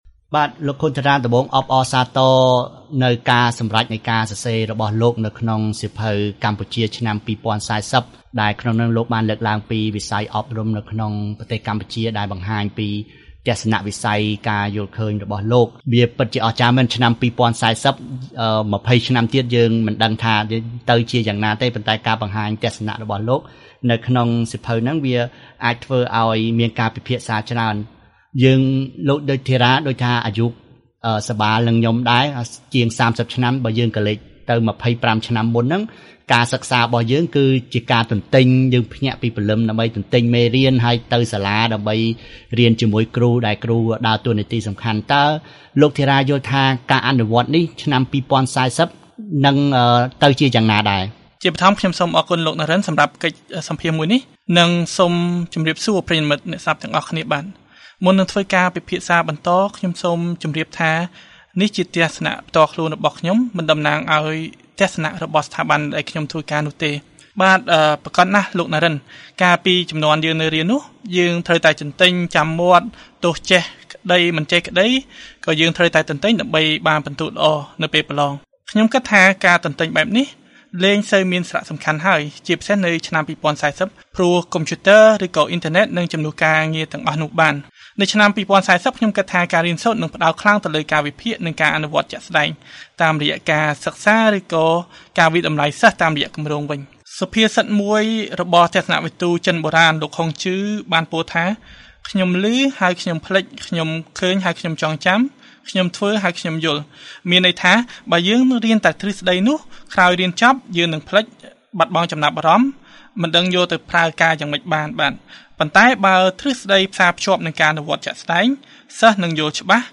បទសម្ភាសន៍ VOA៖ សម្លឹងទៅមុខឆ្នាំ២០៤០ គរុកោសល្យនិងហេដ្ឋារចនាសម្ព័ន្ធក្នុងវិស័យអប់រំកម្ពុជា